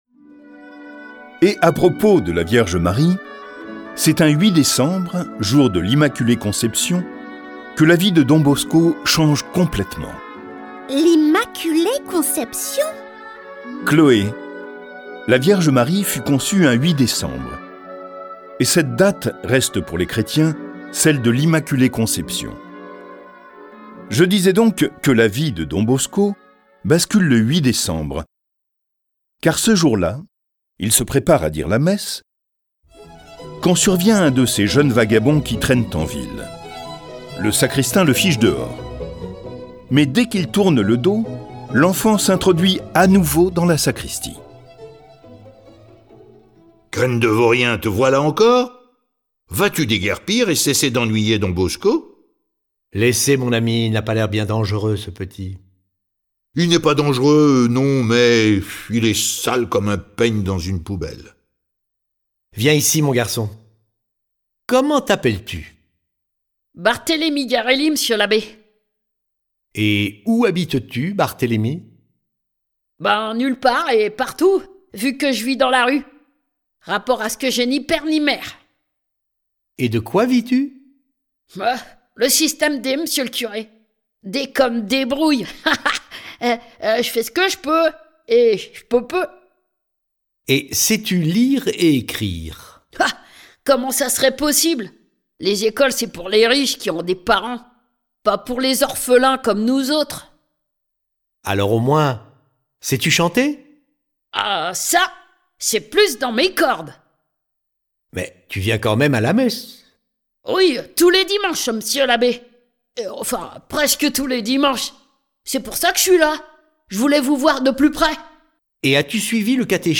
Diffusion distribution ebook et livre audio - Catalogue livres numériques
Cette version sonore de sa vie est animée par 8 voix et accompagnée de plus de 30 morceaux de musique classique.